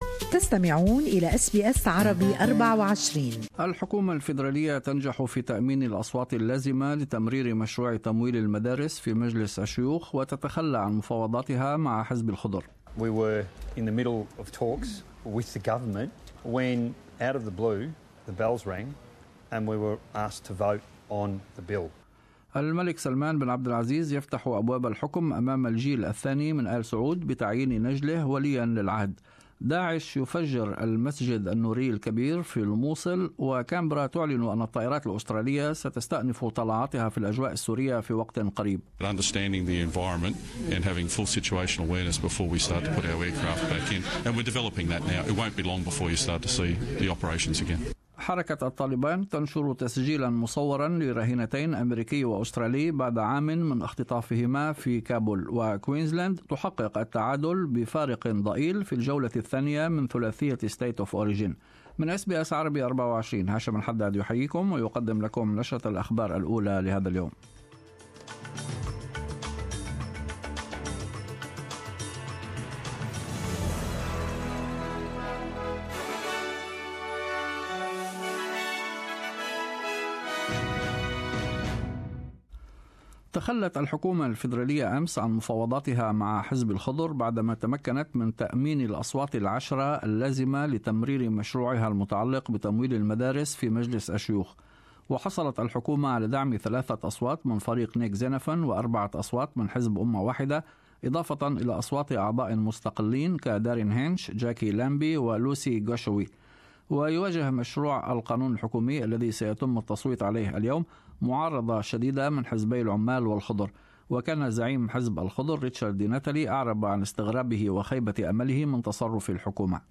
Morning news bulletin.